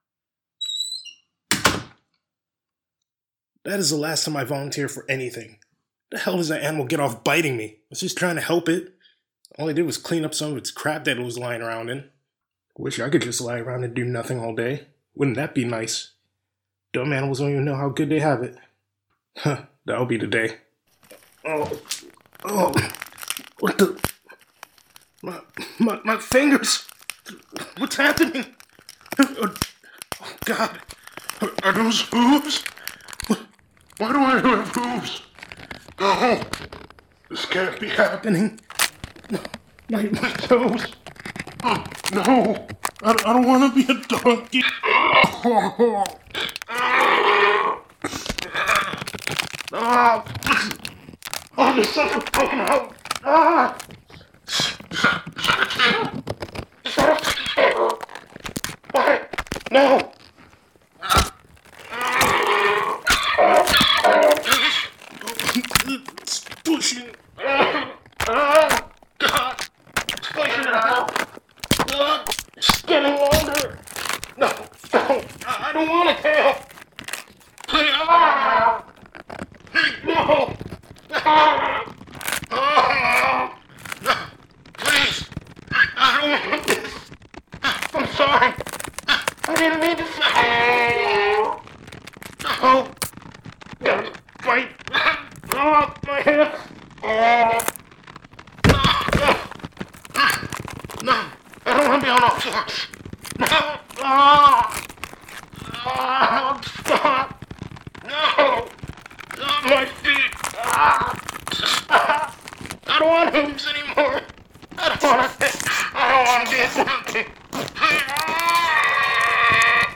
bones cracking